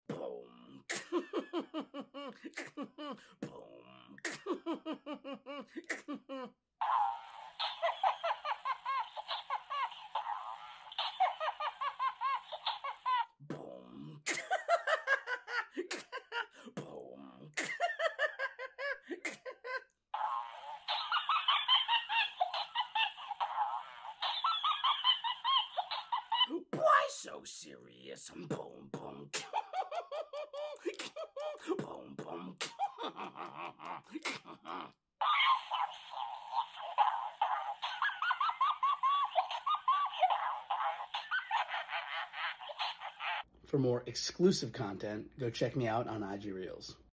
Parrot Laugh Emoji Beatbox 🦜🤡 Sound Effects Free Download